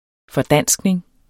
Udtale [ fʌˈdanˀsgneŋ ]